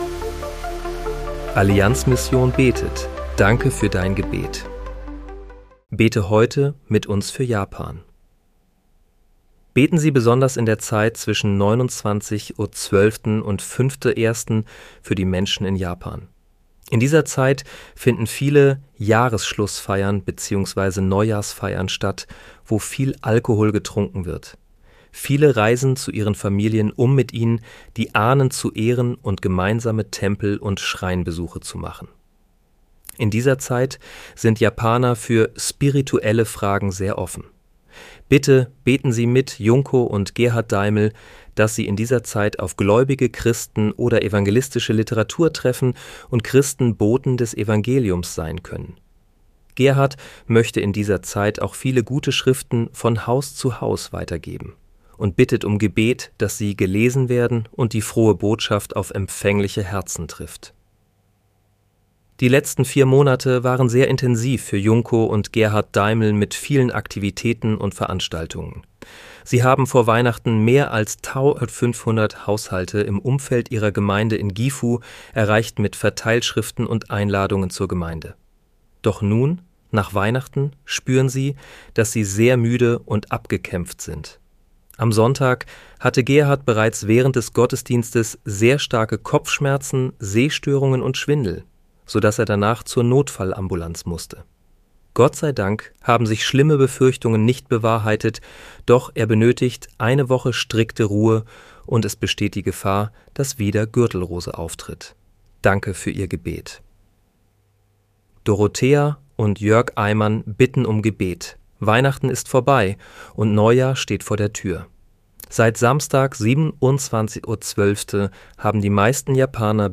Bete am 31. Dezember 2025 mit uns für Japan. (KI-generiert mit der